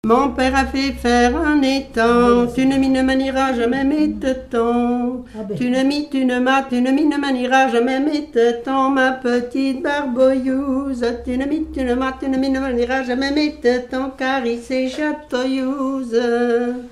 Mémoires et Patrimoines vivants - RaddO est une base de données d'archives iconographiques et sonores.
Genre laisse
Catégorie Pièce musicale inédite